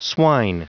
Prononciation du mot swine en anglais (fichier audio)
Prononciation du mot : swine